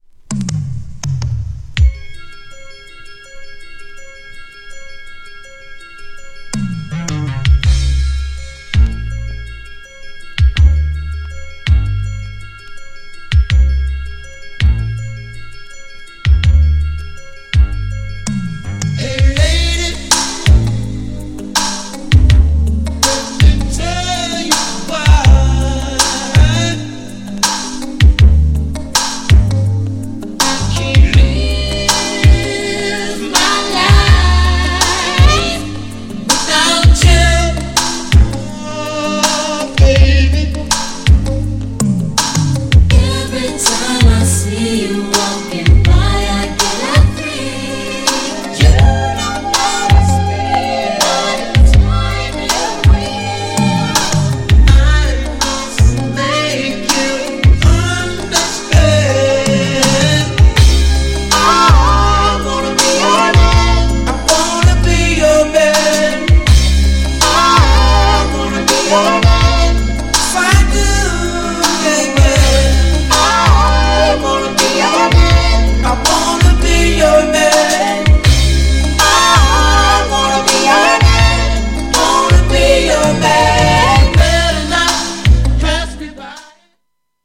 "I WANT TO〜"もメロウネタの中でも最高峰な名曲!!
GENRE R&B
BPM 106〜110BPM